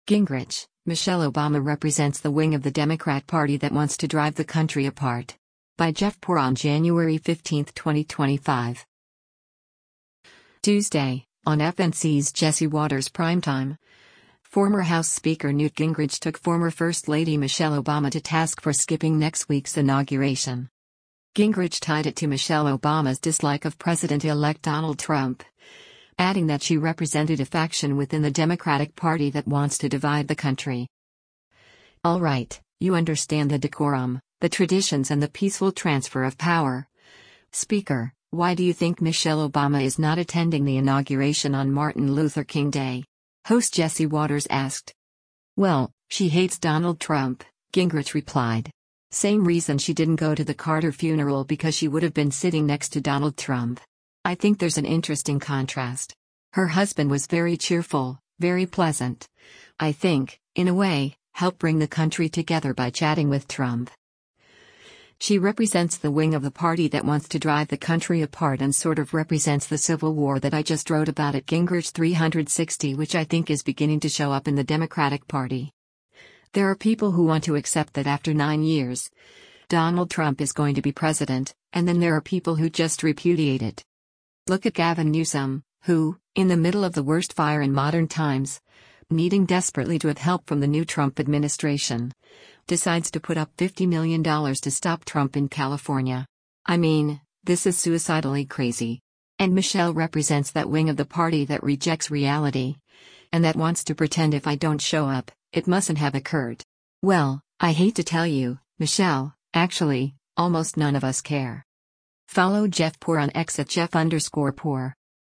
Tuesday, on FNC’s “Jesse Watters Primetime,” former House Speaker Newt Gingrich took former first lady Michelle Obama to task for skipping next week’s inauguration.